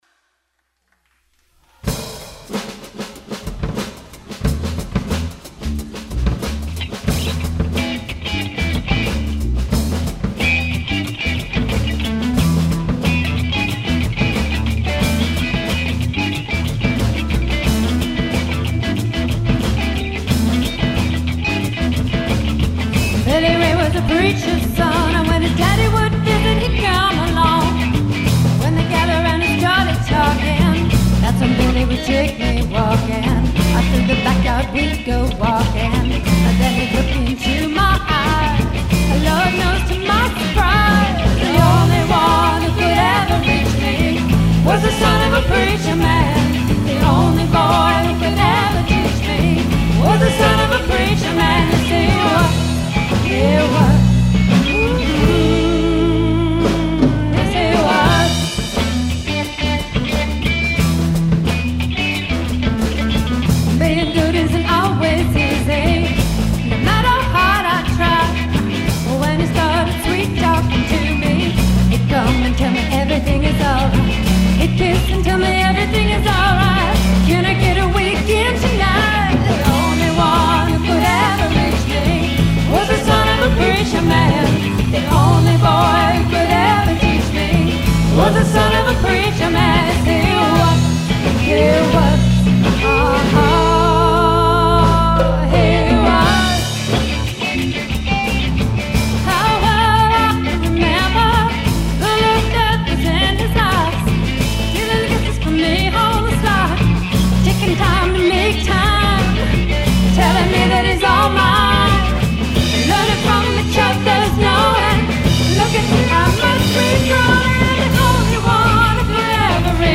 Recorded 2/24/07 at River Bend Bar & Grill in Newfane, VT